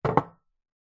Небольшой стеклянный кувшин поставили на деревянный стол 3